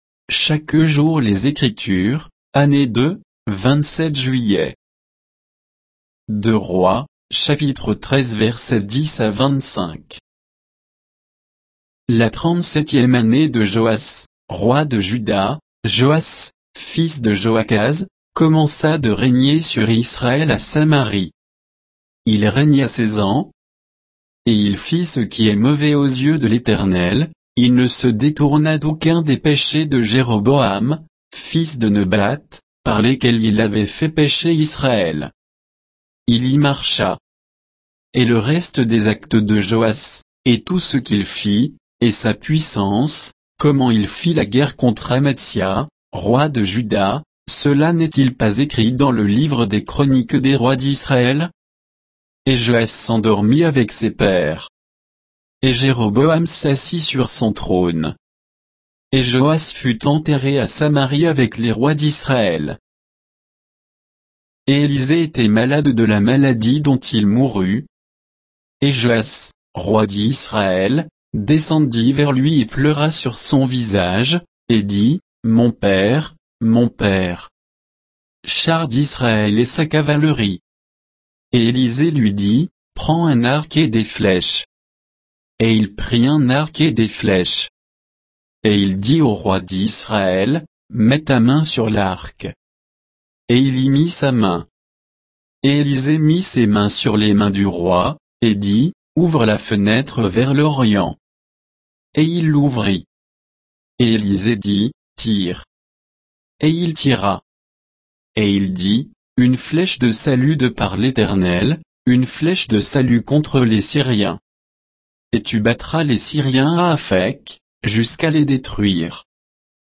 Méditation quoditienne de Chaque jour les Écritures sur 2 Rois 13, 10 à 25